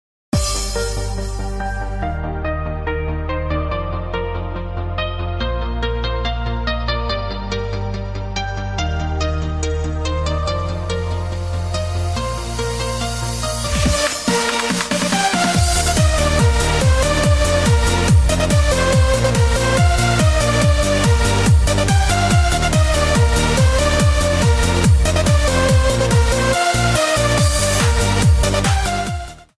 Género musical: Instrumental